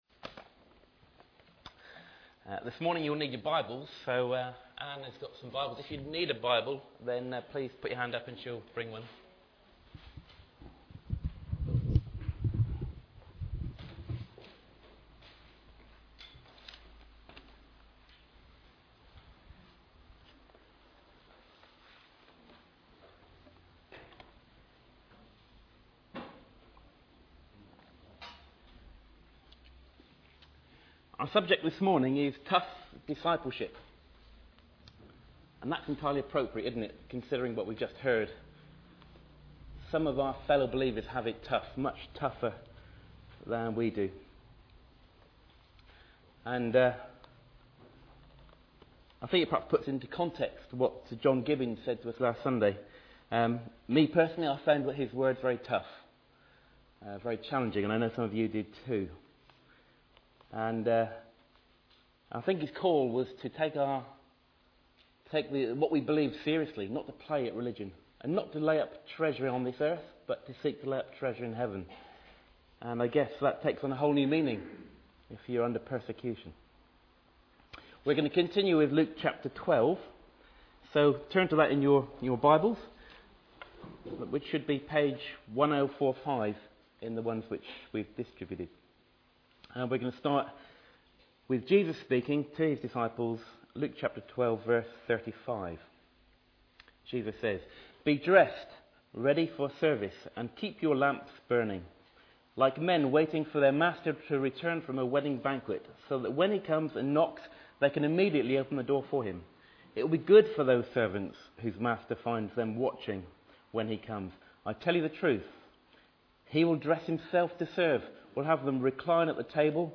Saltisford Church - How should you live? (part 1)